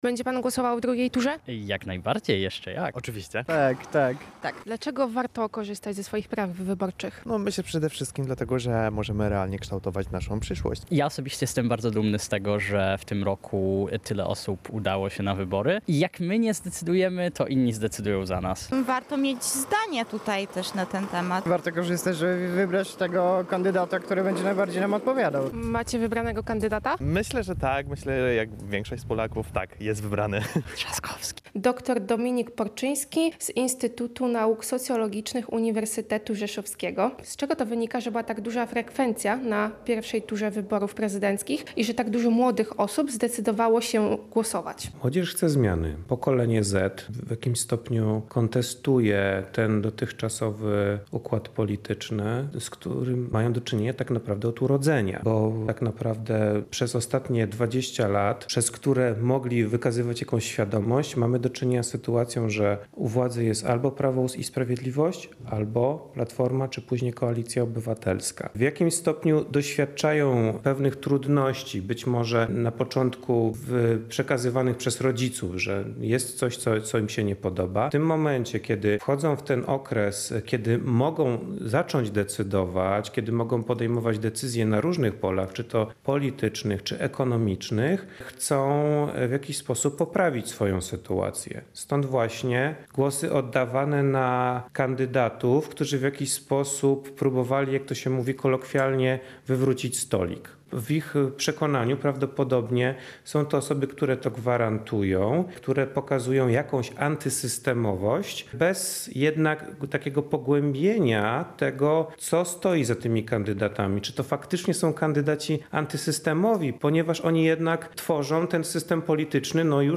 Zapytani o udział w wyborach odpowiadają, że głosowanie to nie tylko przywilej, ale również obowiązek obywatelski.